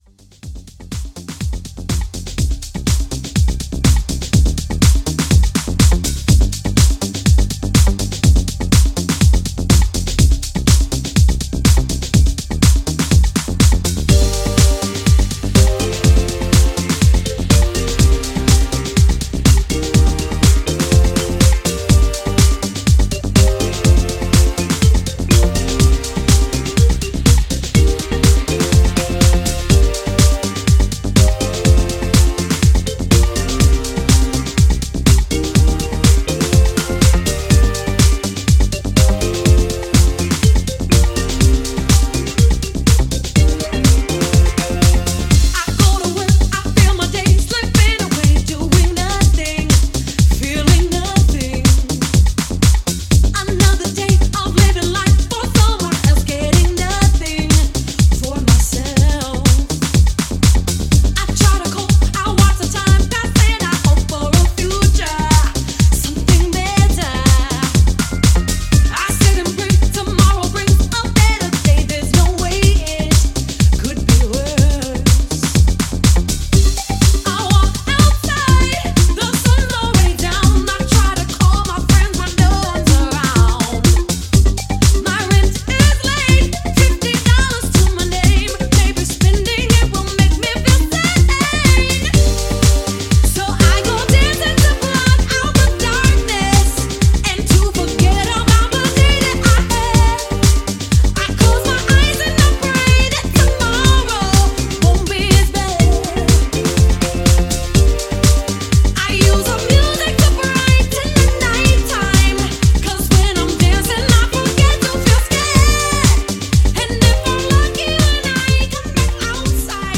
classic rave sounds